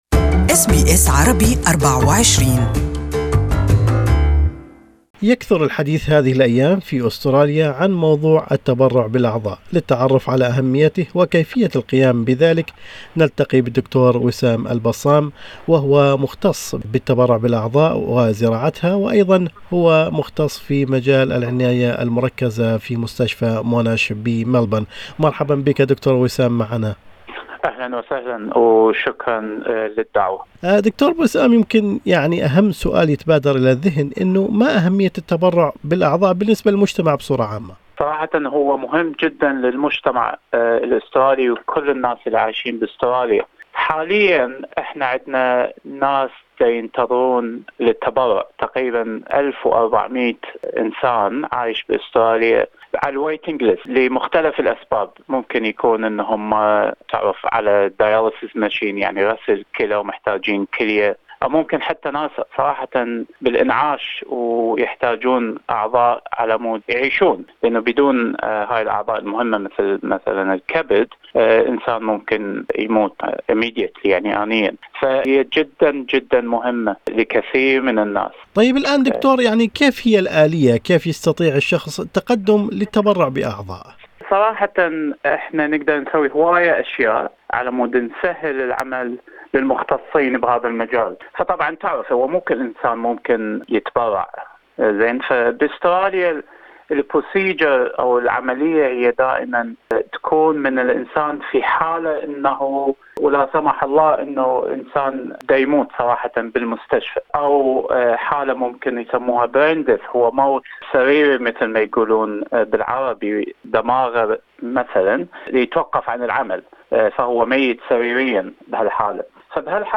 كان لنا هذا اللقاء